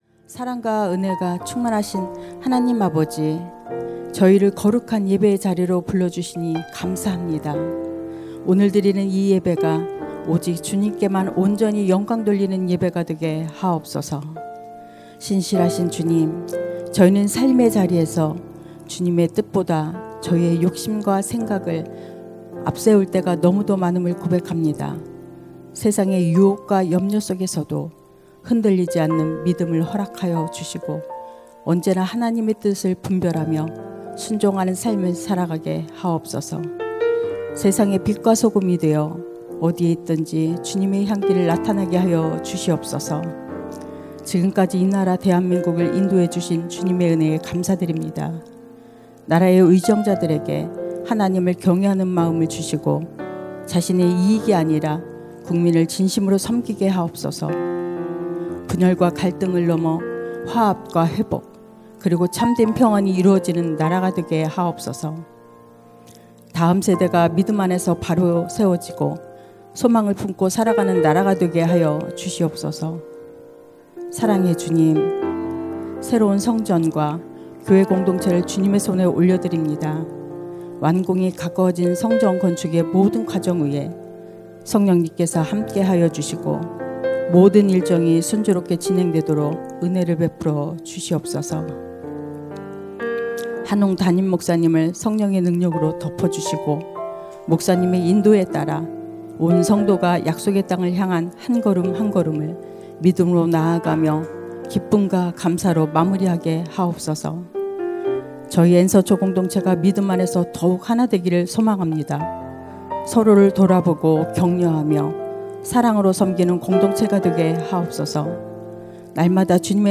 > 설교 - 새로운교회
[새벽예배]